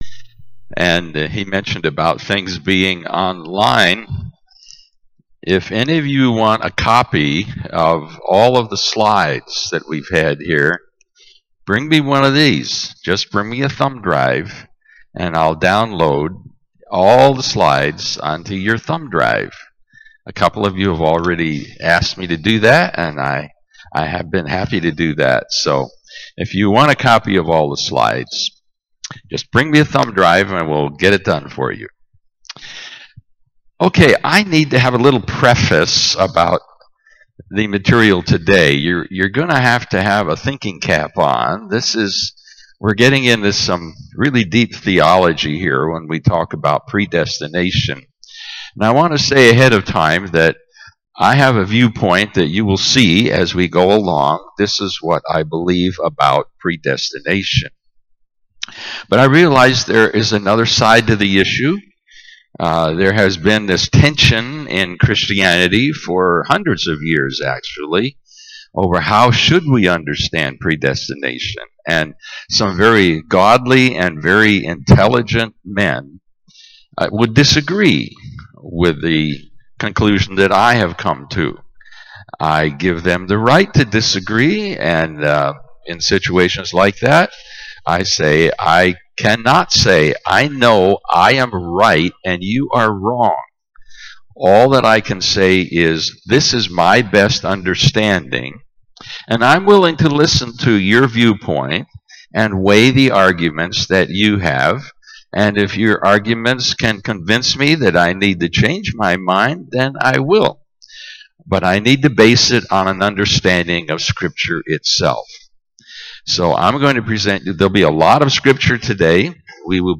Family Camp 2022